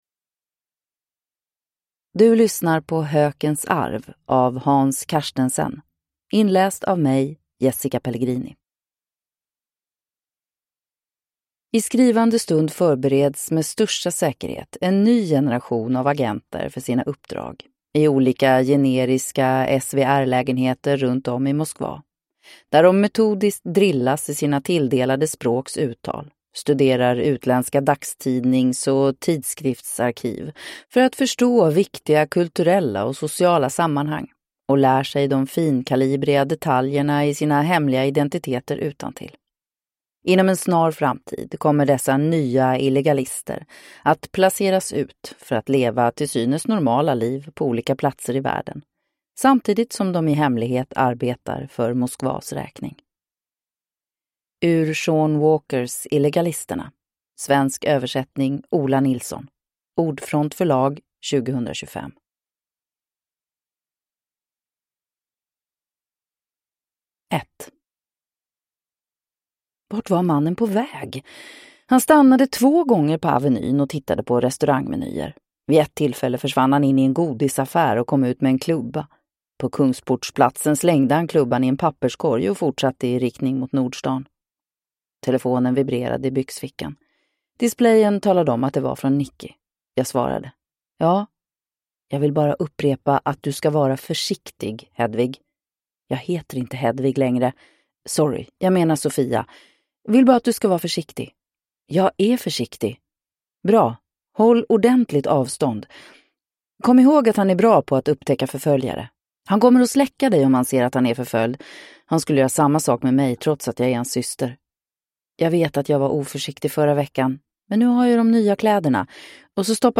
Hökens arv (ljudbok) av Hans Carstensen